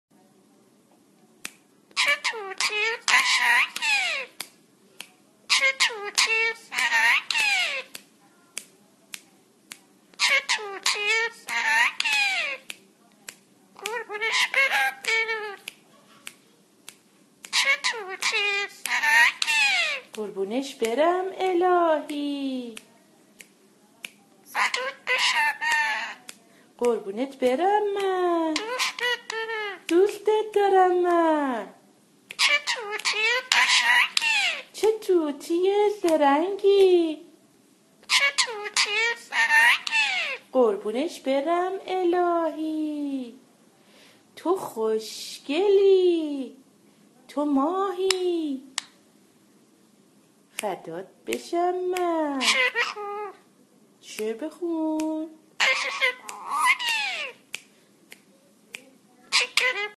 صدای صوتی طوطی برای آموزش و تمرین